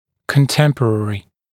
[kən’temprərɪ][кэн’тэмпрэри]современный; существующие в одно время; современник